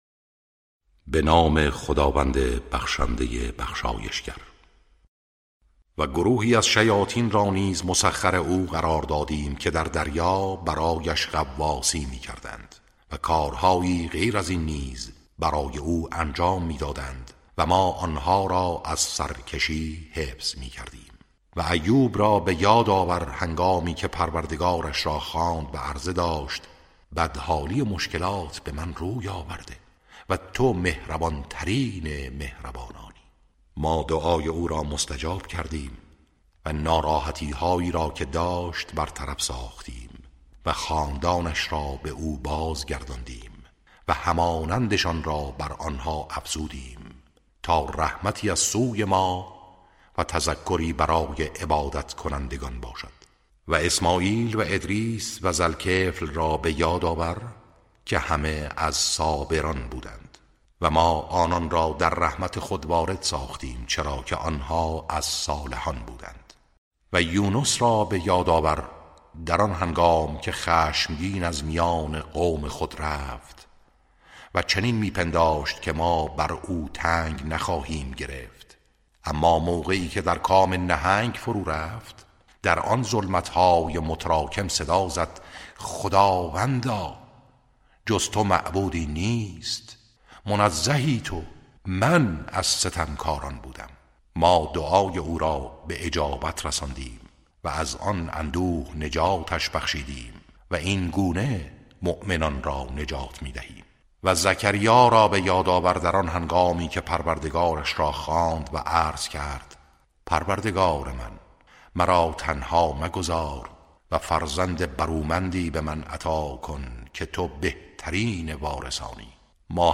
ترتیل صفحه ۳۲۹ سوره مبارکه انبیاء(جزء هفدهم)
ترتیل سوره( انبیاء)